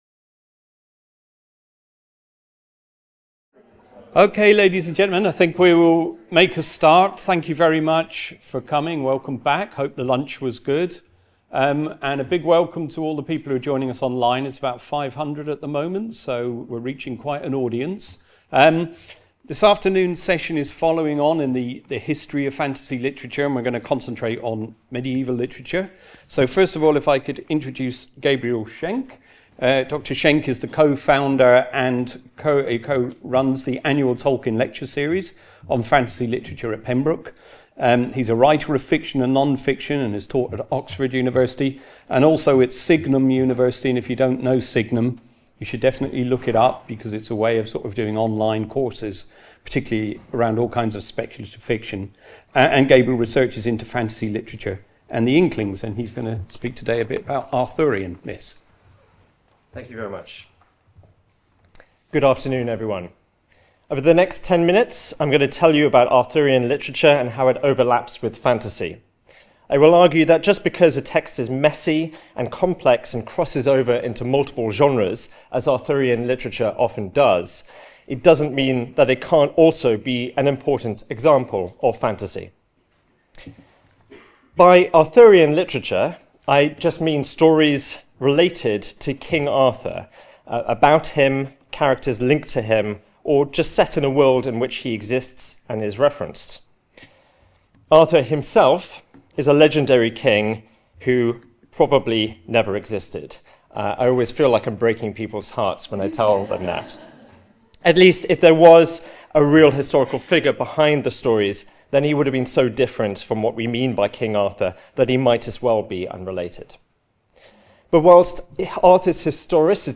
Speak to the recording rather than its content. Short talk (10 minutes) - Part of the Bloomsbury-Oxford Summer School (23rd-25th September 2025) held at Exeter College.